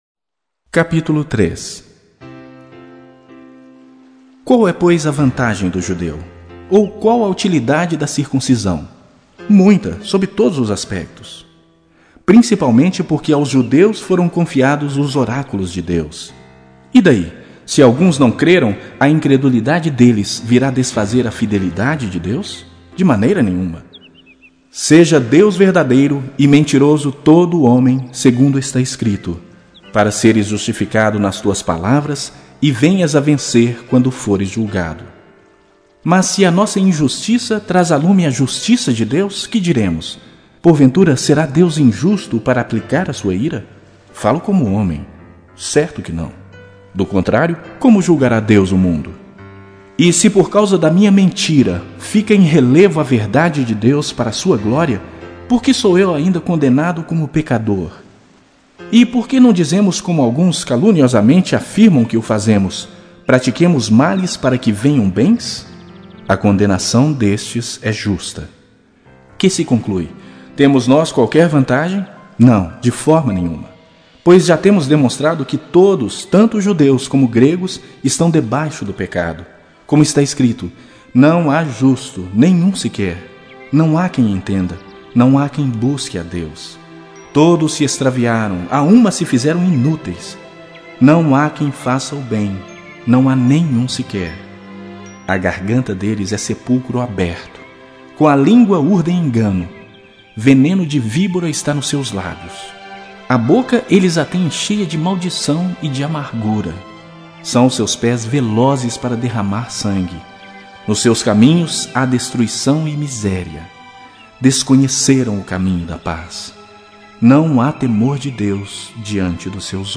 A Bíblia Falada - 06 - Romanos - Novo Testamento